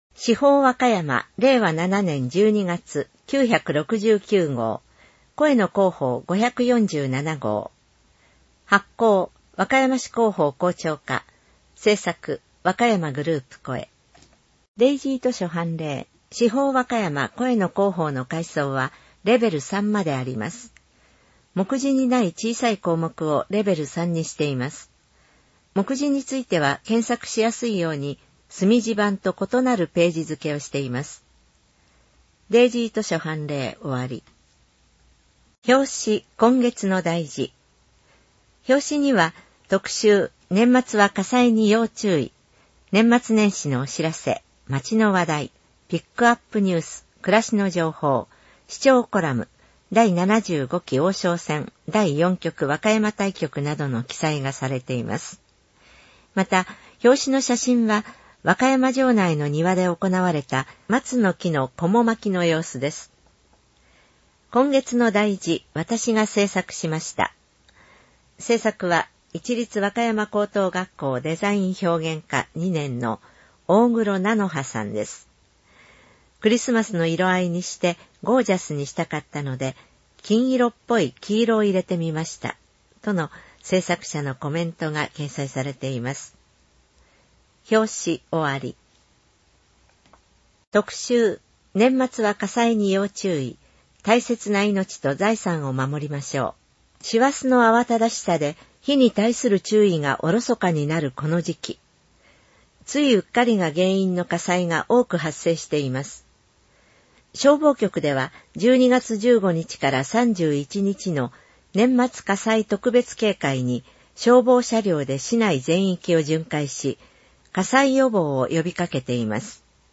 声の市報